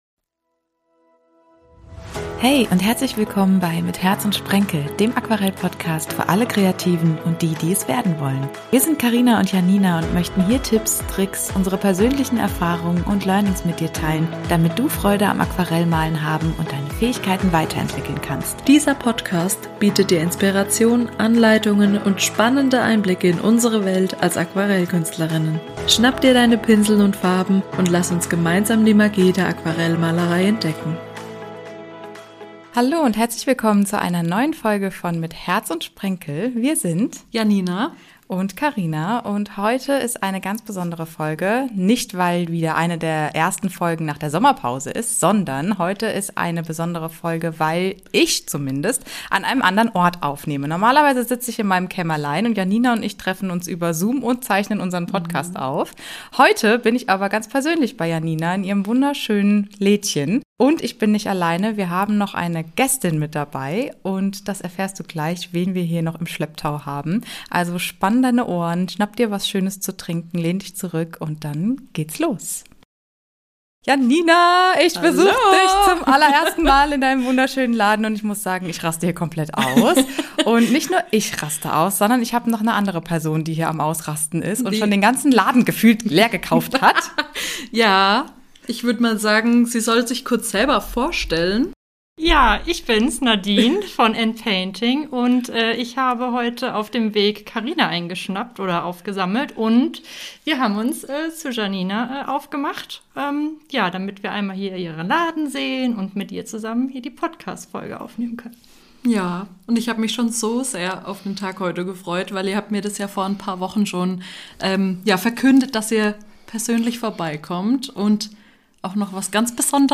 sondern wir sind unterwegs und haben eine ganz besondere Person im Schlepptau.